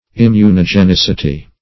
immunogenicity - definition of immunogenicity - synonyms, pronunciation, spelling from Free Dictionary
immunogenicity.mp3